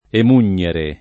emugnere [ em 2 n’n’ere ]